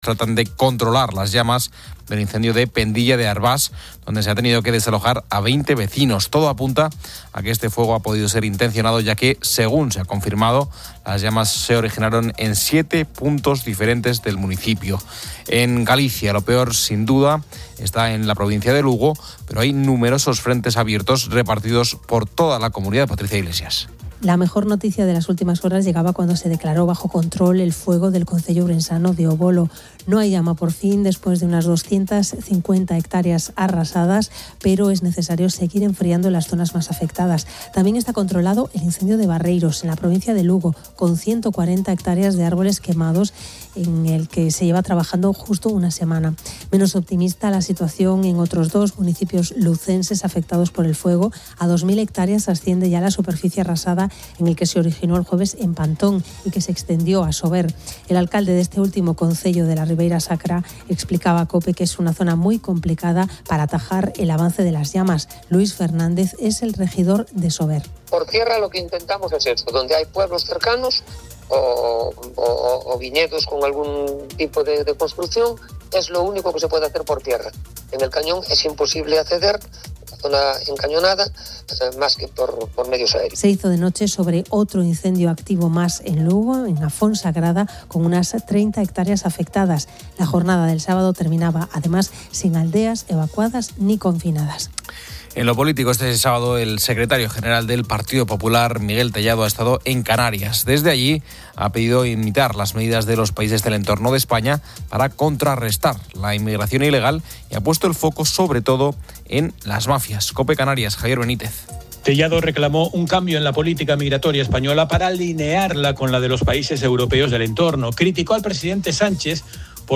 En el audio se escucha a diferentes locutores de COPE, que repasan las noticias del día en varios ámbitos.